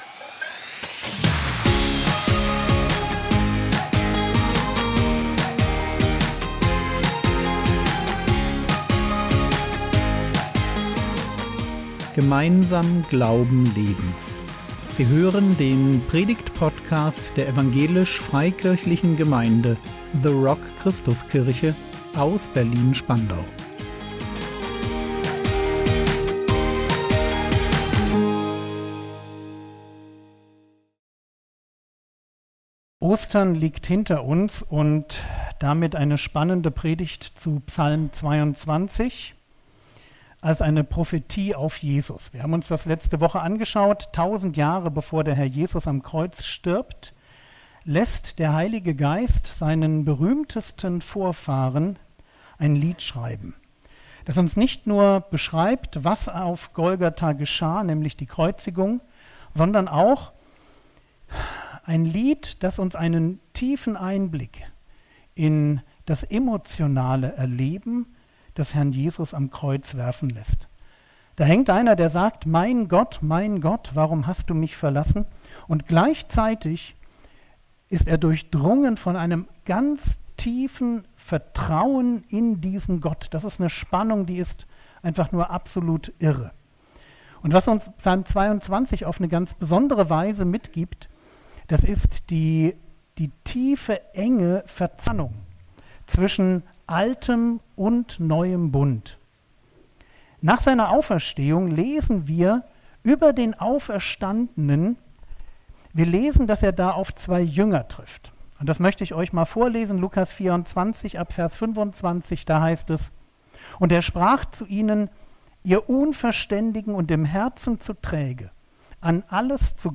Der Weg durch die Wüste | 27.04.2025 ~ Predigt Podcast der EFG The Rock Christuskirche Berlin Podcast